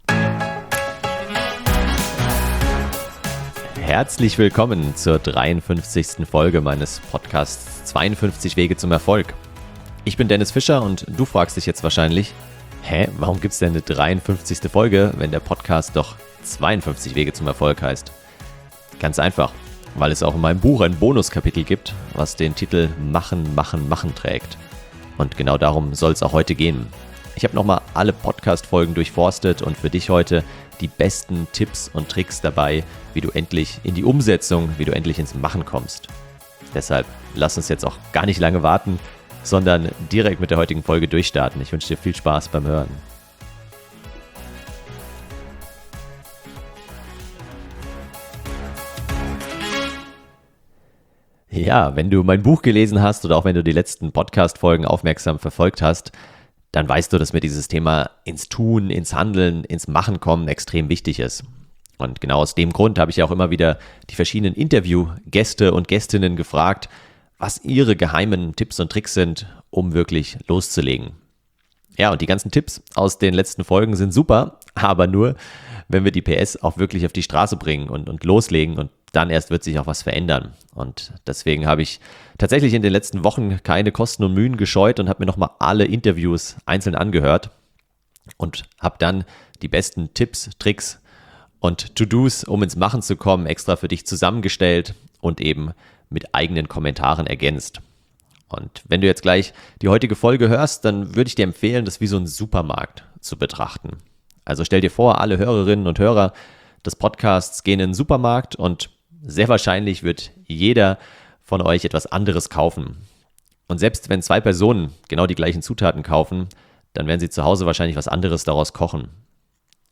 Die besten Antworten habe ich dir in diesem Podcast zusammengeschnitten und für dich kommentiert.